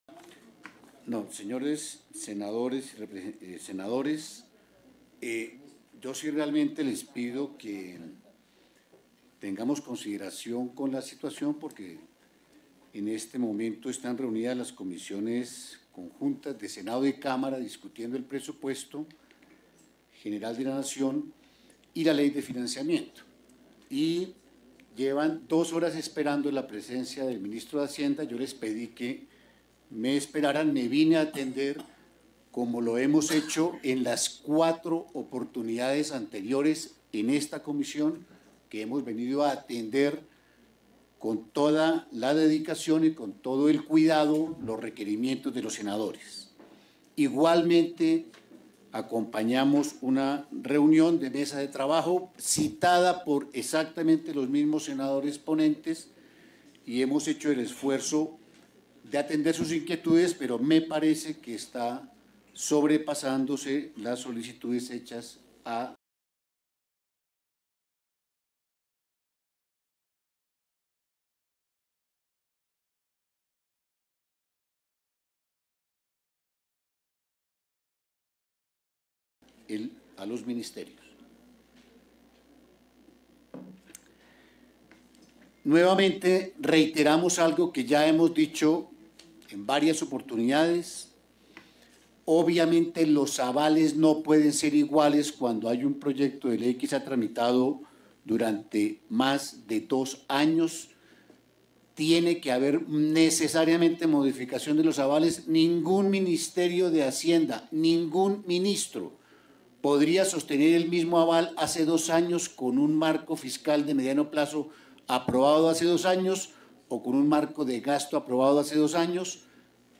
Intervención del ministro de Hacienda en la Comisión VII de Senado sobre Reforma a la Salud – 9 de septiembre de 2025
Stereo